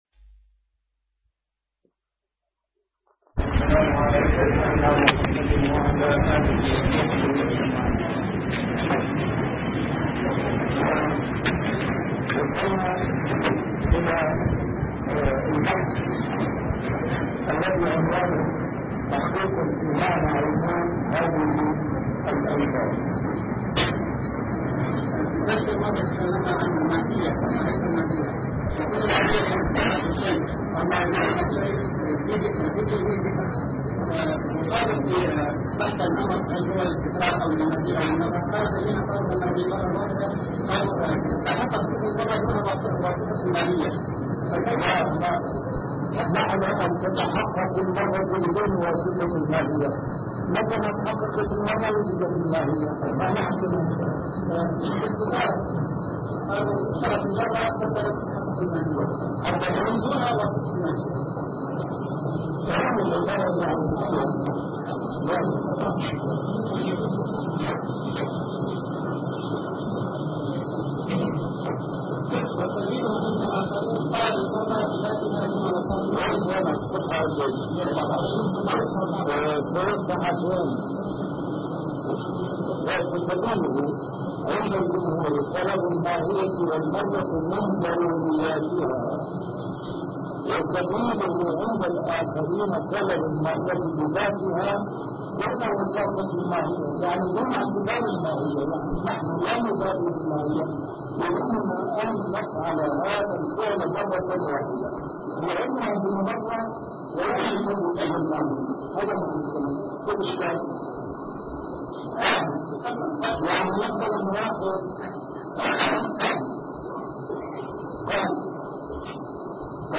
A MARTYR SCHOLAR: IMAM MUHAMMAD SAEED RAMADAN AL-BOUTI - الدروس العلمية - مباحث الكتاب والسنة - الدرس الخامس عشر: دلالات الألفاظ من حيث درجة الشمول ونوعه فيها [اللفظ العام]